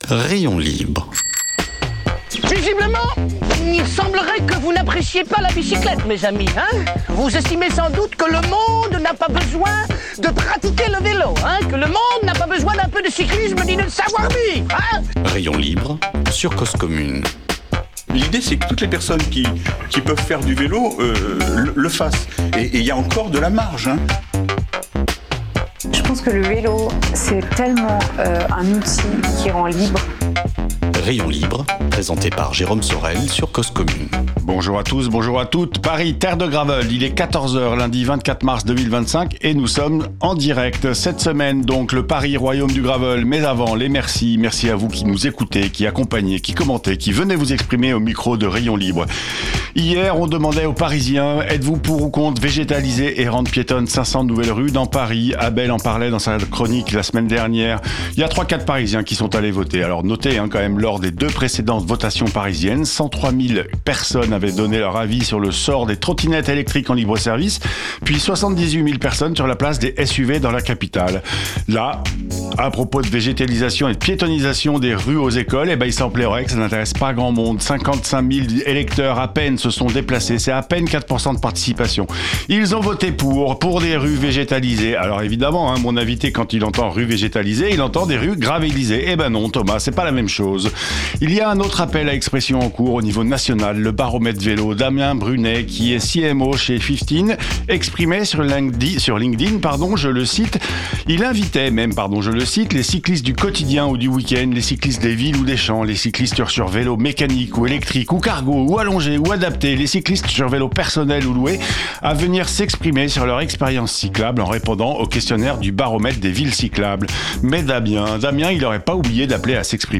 Émission proposée en partenariat avec Weelz! . En plateau